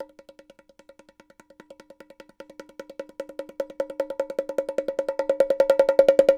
Bongo Buildup 02.wav